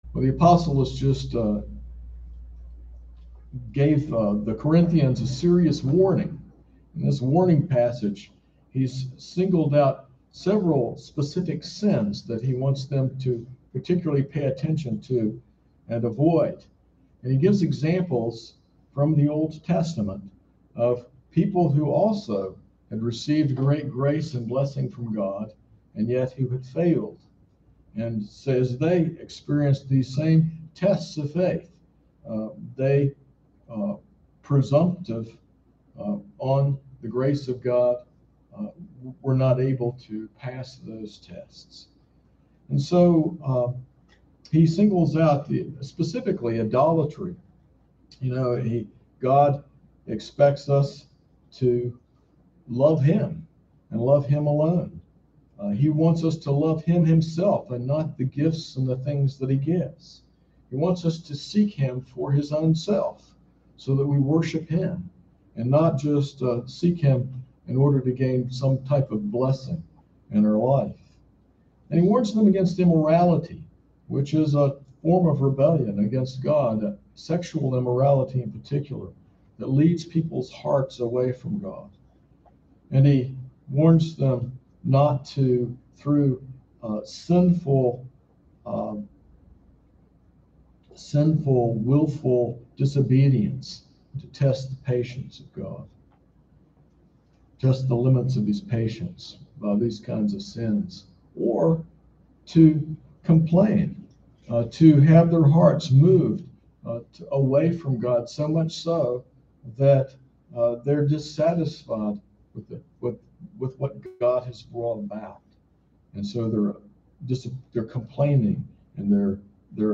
This sermon explores 1 Corinthians 10:13, emphasizing that all believers face common temptations. It encourages trust in God’s faithfulness, who provides a tailored way of escape and enables endurance.
sermon-9-26-21.mp3